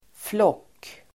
Ladda ner uttalet
Uttal: [flåk:]